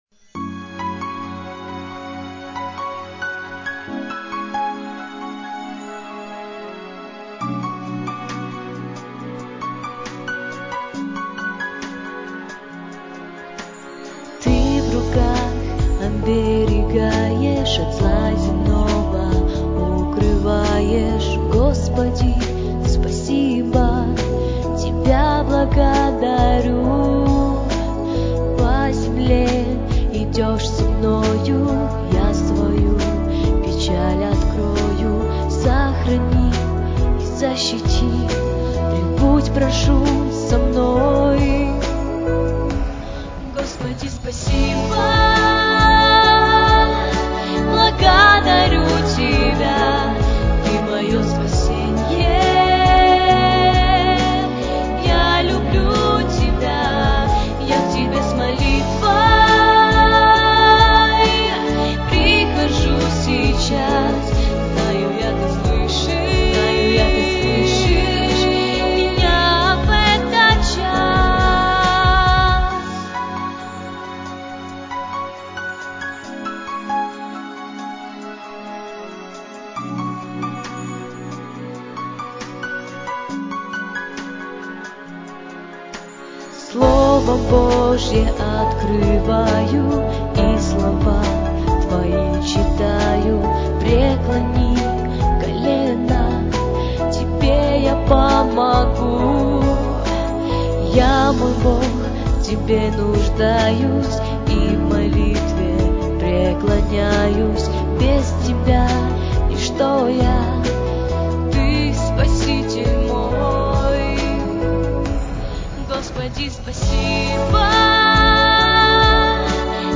on 2015-12-27 - Молитвенное пение